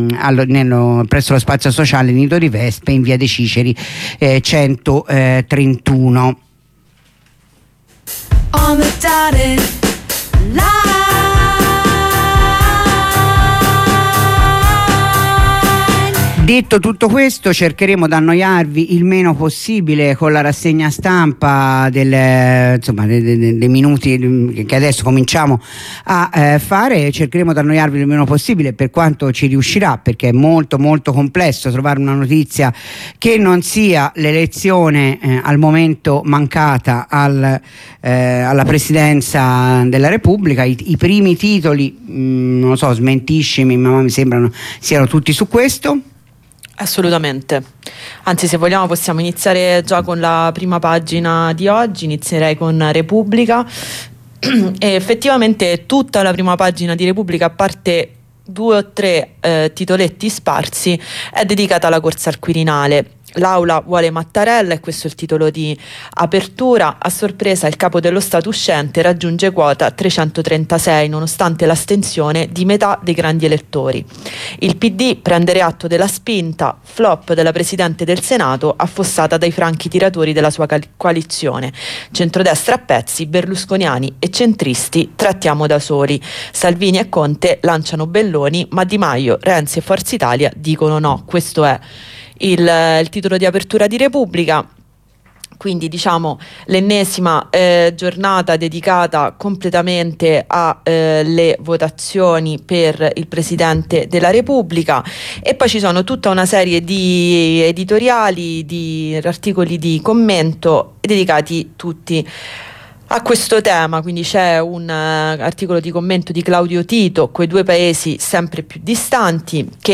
Rassegna stampa di sabato 29 gennaio